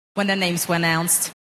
The speaker, Alice Tumler, has a basically Southern-British type of accent; according to her Wikipedia page, she studied for some time in London.
In that clip she’s saying ‘when their names were announced’, blurring were into announced without a linking r to keep the vowels apart.